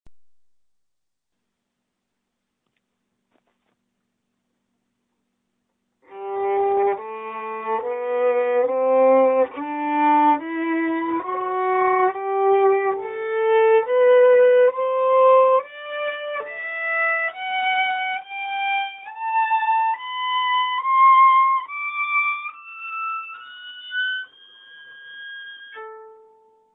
というわけで、簡単なｽｹｰﾙで音色の違いを確認してみました！
1本めの弓(木・たぶん中国製)
1本めと3本めは響きのﾚﾍﾞﾙは同じくらい。2本めが一番響きますね？